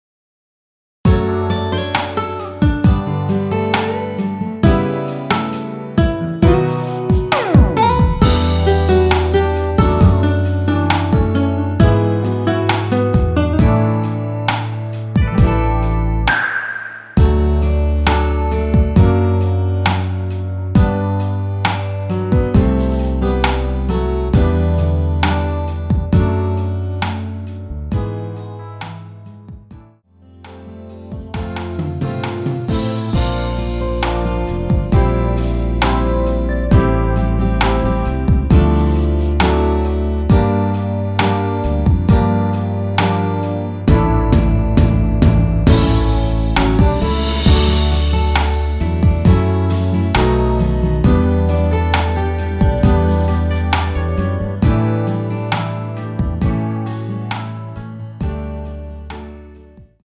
◈ 곡명 옆 (-1)은 반음 내림, (+1)은 반음 올림 입니다.
앞부분30초, 뒷부분30초씩 편집해서 올려 드리고 있습니다.
중간에 음이 끈어지고 다시 나오는 이유는